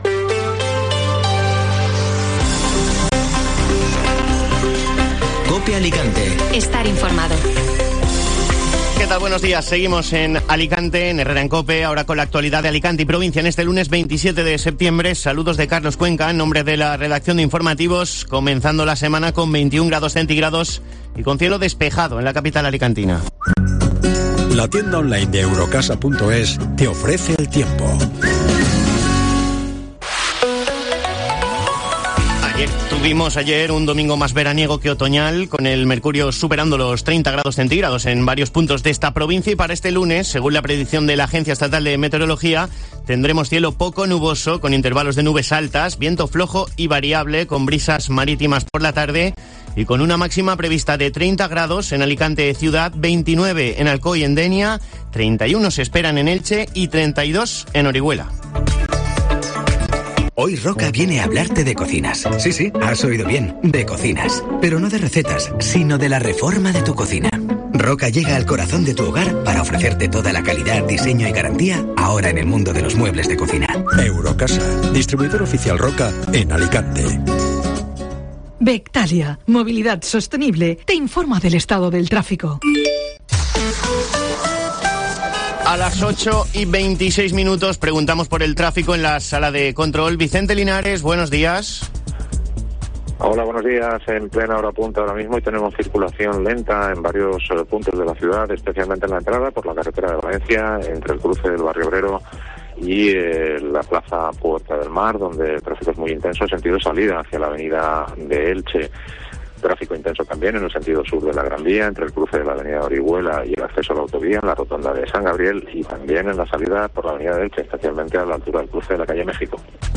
Informativo Matinal Alicante (Lunes 27 de septiembre)